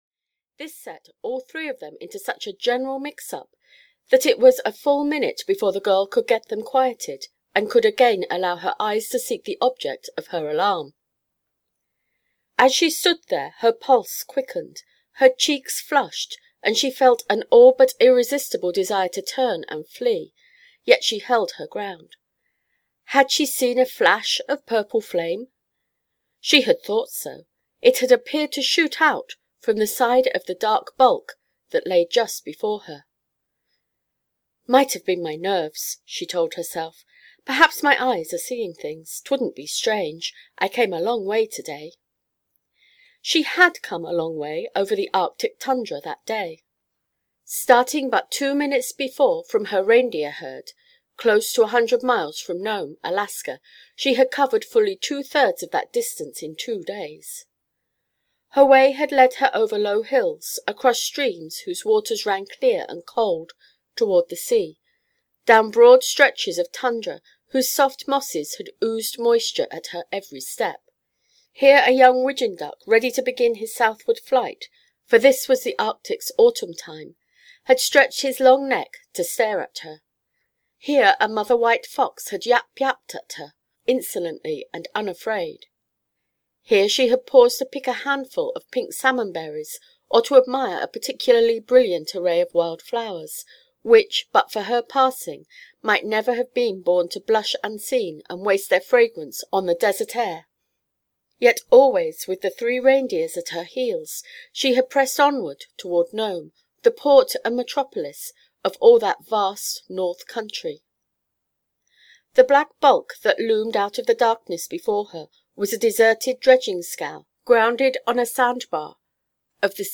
The Purple Flame (EN) audiokniha
Ukázka z knihy